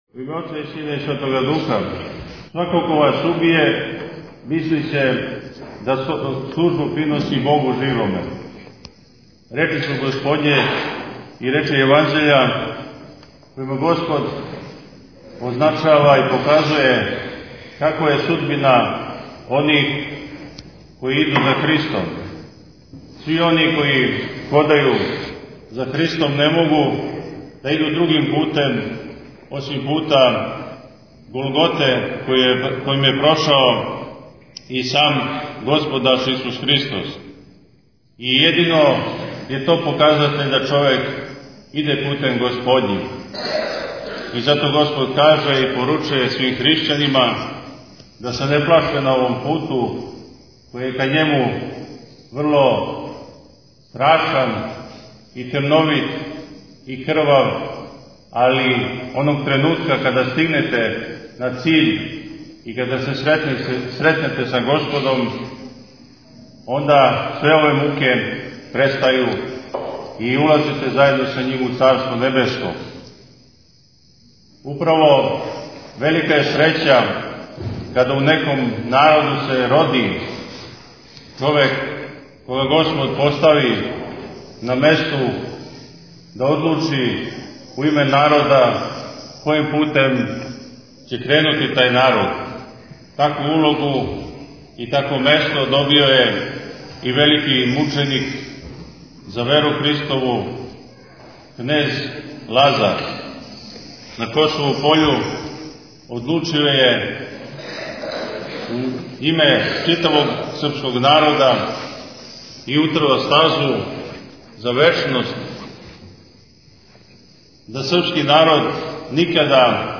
Tagged: Бесједе
на светој литургији коју је служио на празник Видовдан у манастиру Савини